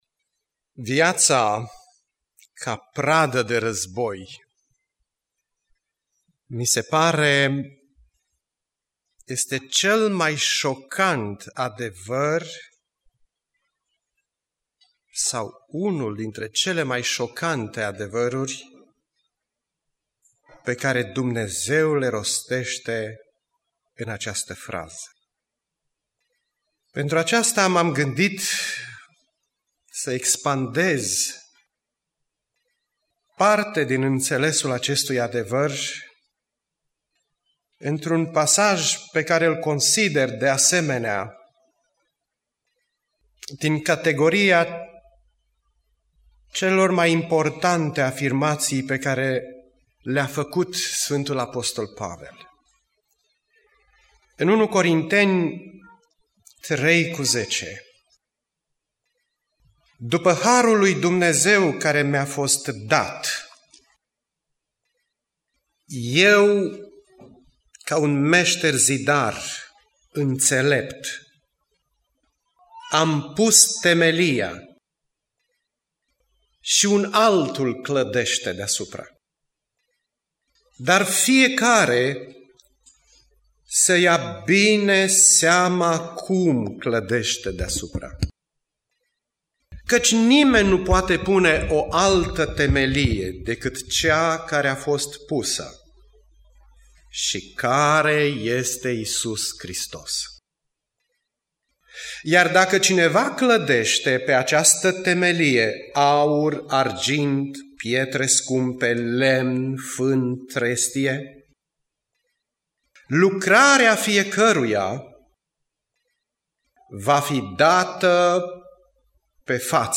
Predica Aplicatie- Ieremia 42-45